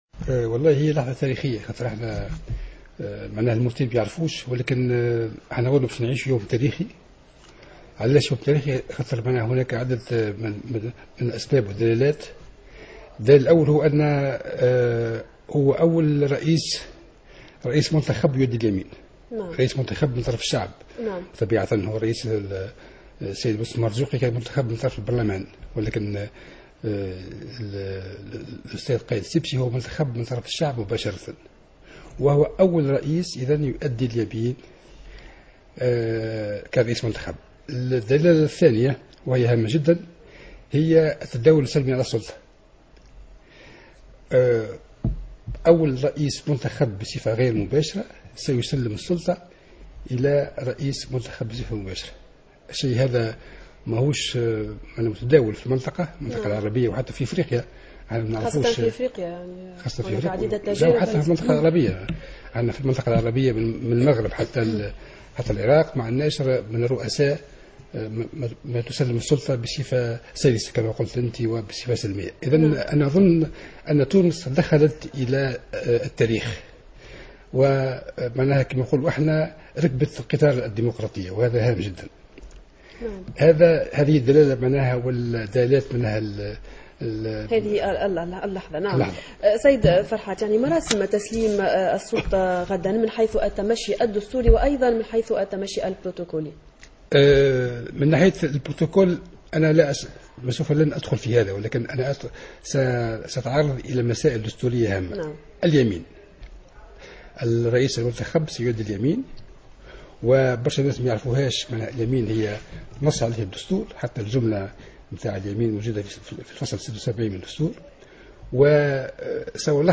اعتبر استاذ القانون الدستوري فرحات الحرشاني في تصريح للقناة الوطنية الاولى اليوم الثلاثاء أن تونس ستعيش إذا على وقع حدث تاريخي على اعتبار أن الباجي قائد السبسي سيتسلم السلطة ويؤدي اليمين كأول رئيس منتخب من قبل الشعب، علاوة على أن تسليم السلطة سيتم بصفة سلمية وسلسة.